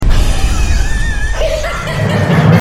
laugh
jokerlaugh.mp3